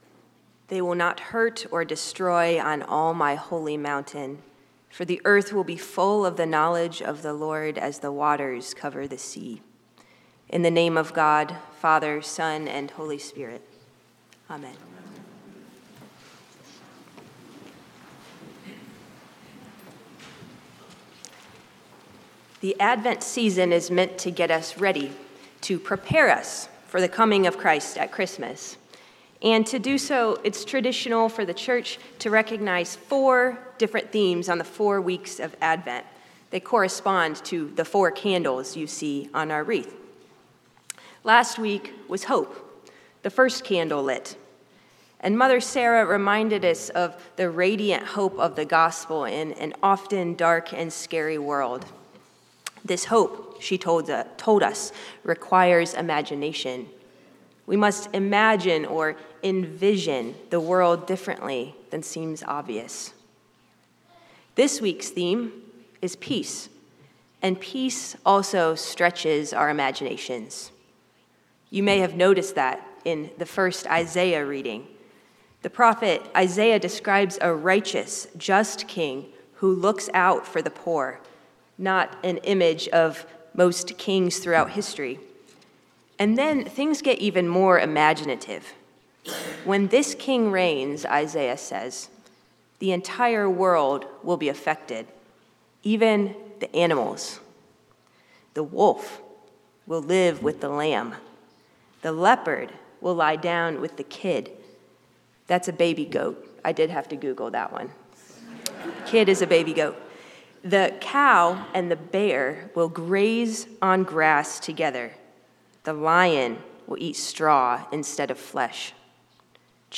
St-Pauls-HEII-9a-Homily-07DEC25.mp3